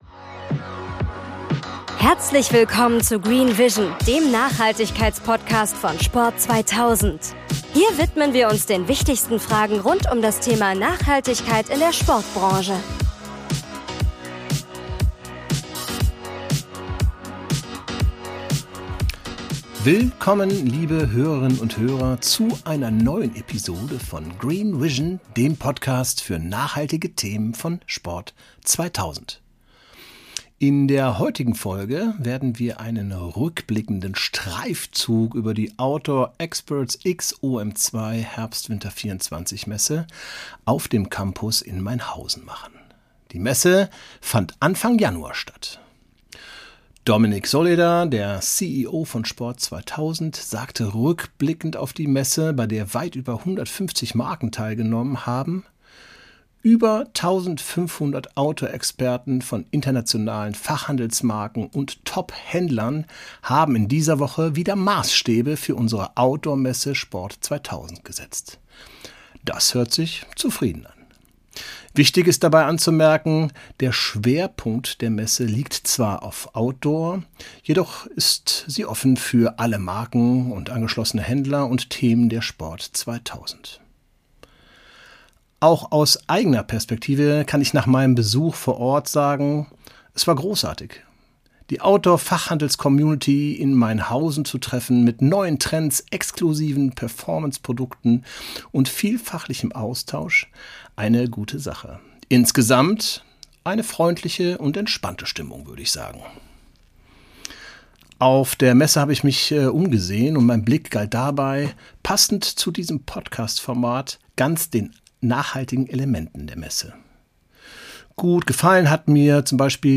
Für die heutige Episode waren wir auf der OUTDOOR EXPERTS x OM2 Ordermesse auf dem Messe Campus Mainhausen unterwegs und haben uns die nachhaltigen Maßnahmen dort angeschaut.